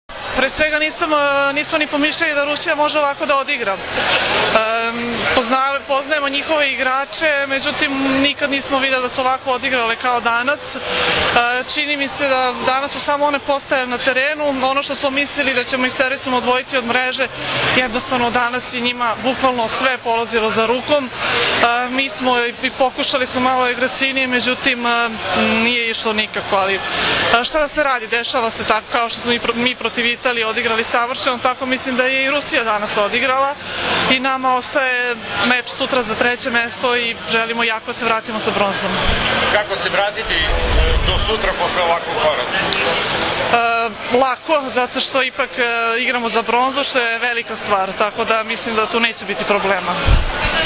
IZJAVA JELENE NIKOLIĆ